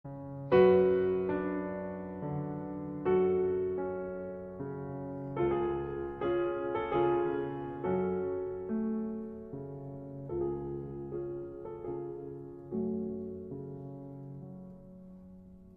يخفي ذلك اللحن عاصفه غاضبه تظهر ومضات منها خلال اللحن كمن فقد السيطره على كبح جماحها للحظات، ثم يتردد صدى جوابها في المساحه القاحله لليأس